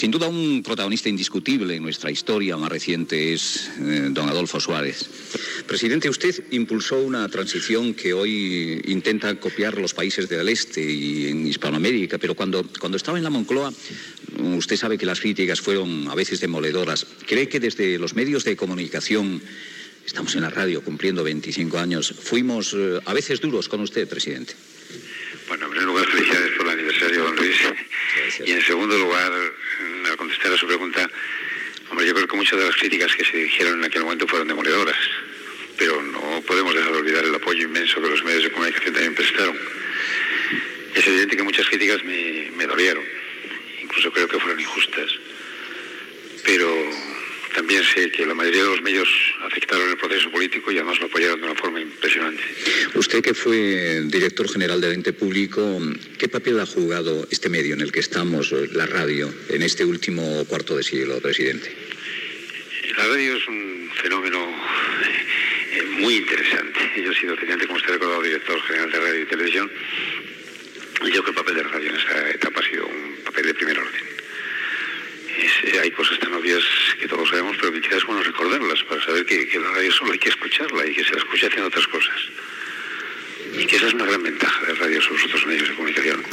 Intervenció telefònica de l'ex president del Govern espanyol Adolfo Suárez a l'emissió del 25è aniversari del programa
Info-entreteniment